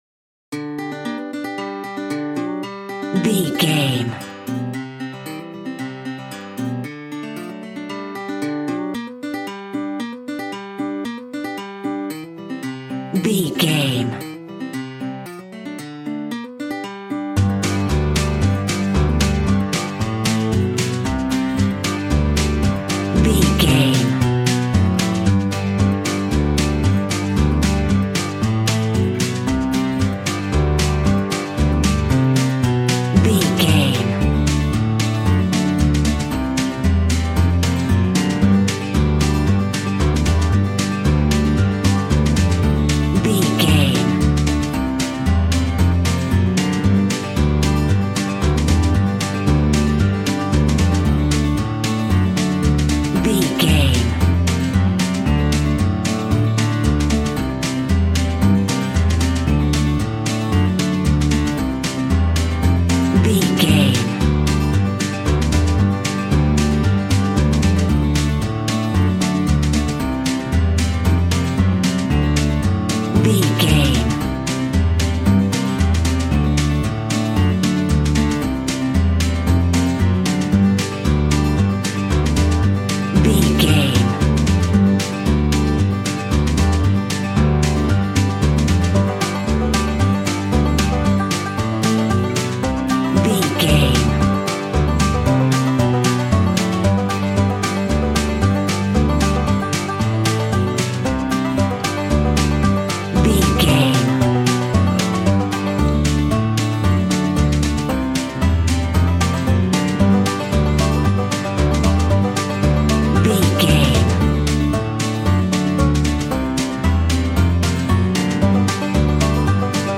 Bouncy and fun country fiddle music.
Ionian/Major
Fast
positive
double bass
drums
acoustic guitar